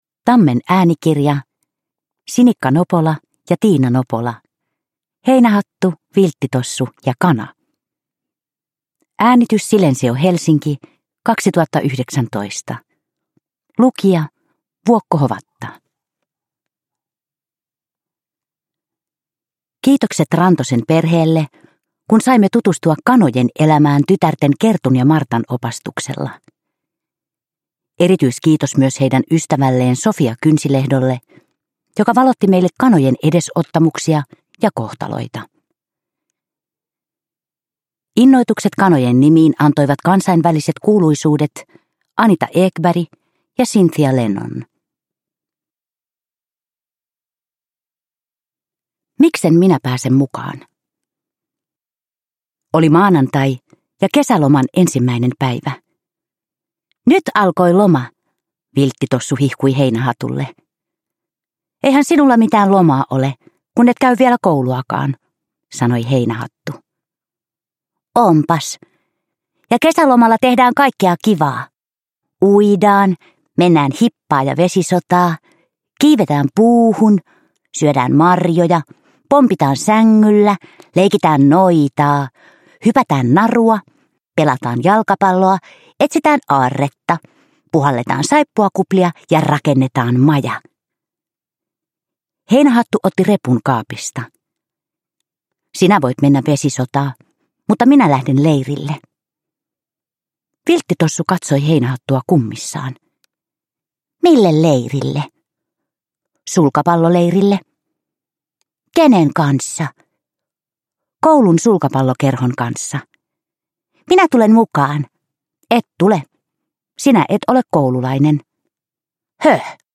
Heinähattu, Vilttitossu ja kana – Ljudbok – Laddas ner